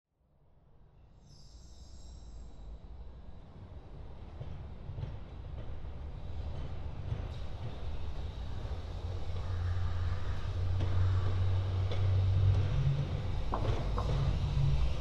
Trolley Passing
Transportation Sound Effects
Trolley20Passing-1-sample.mp3